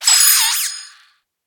Cri de Frissonille dans Pokémon HOME.